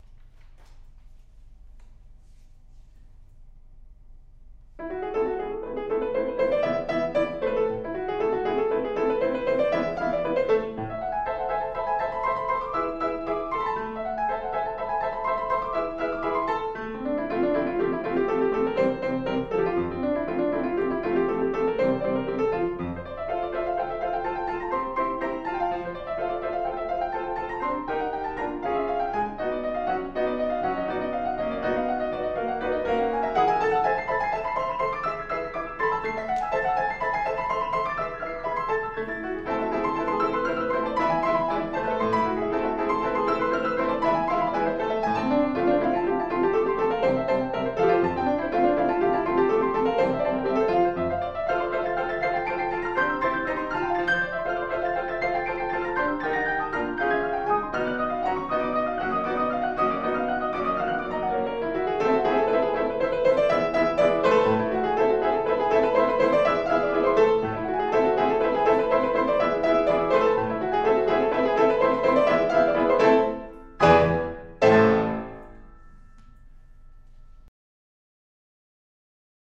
Love Story for Two Pianos
Duet / 2010
Movement 3: Scherzo